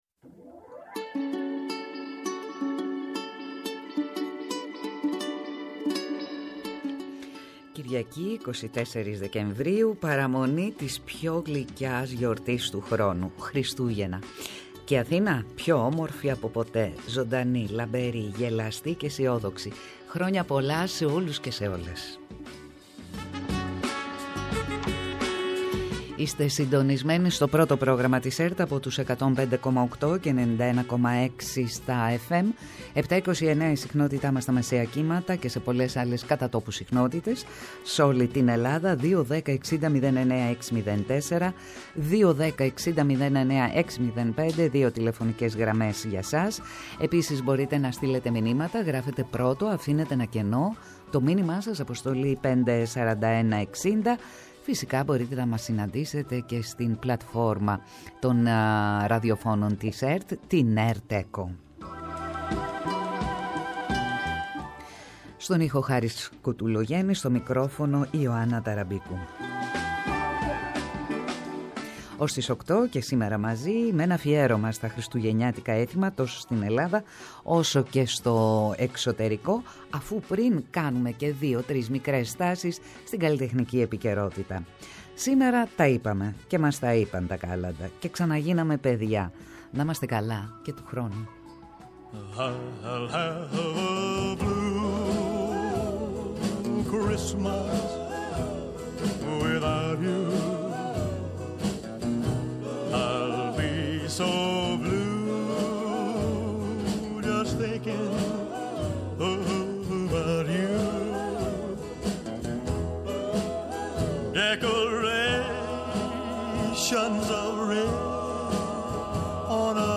Νέα, ειδήσεις, συνεντεύξεις από τον χώρο της τέχνης και του πολιτισμού.